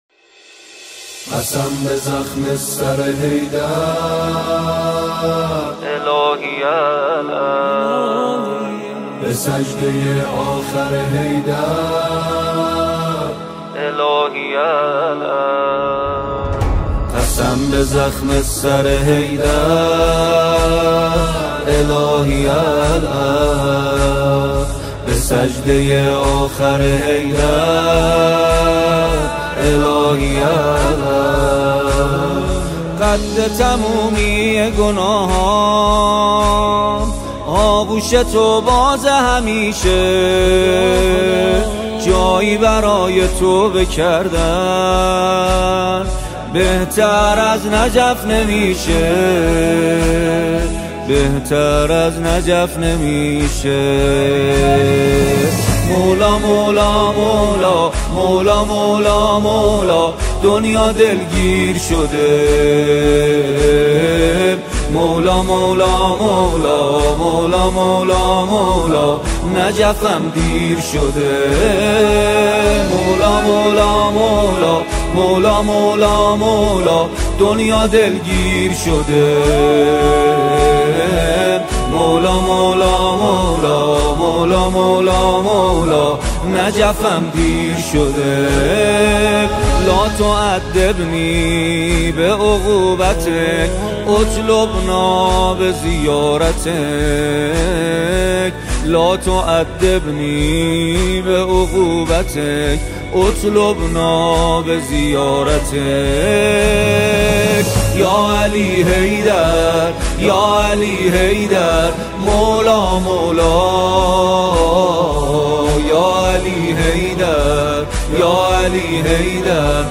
با نوای دلنشین
نماهنگ زیبا و دلنشین